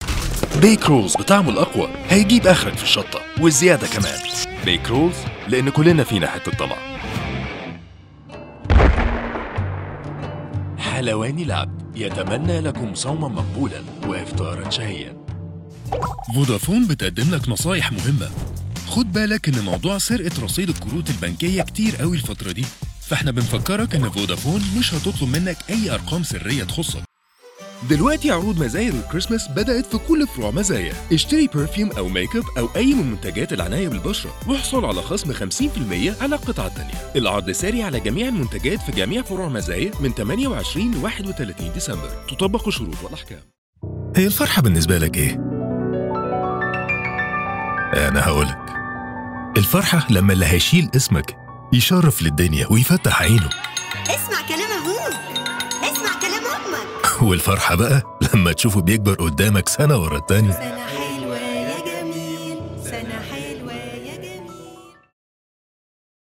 Male Voices